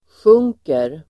Uttal: [sj'ung:ker]